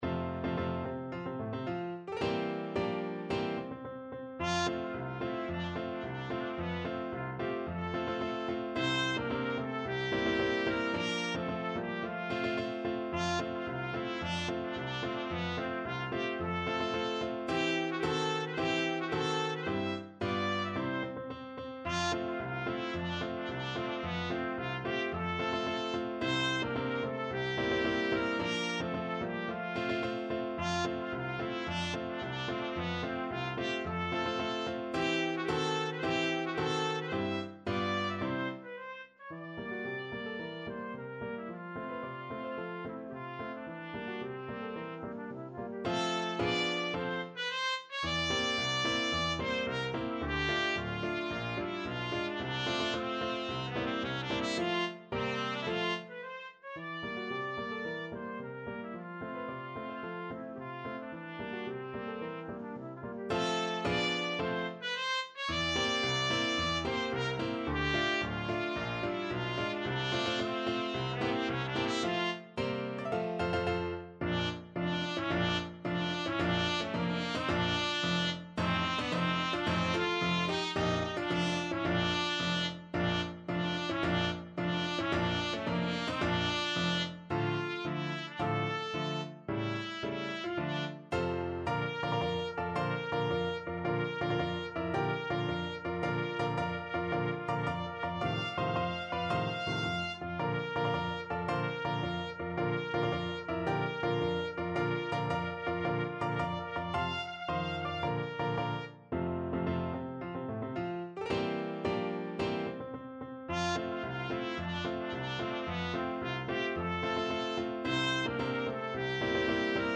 Classical Komzak, Karel Erzherzog Albrecht March Trumpet version
Trumpet
2/2 (View more 2/2 Music)
March =c.110
F major (Sounding Pitch) G major (Trumpet in Bb) (View more F major Music for Trumpet )
Classical (View more Classical Trumpet Music)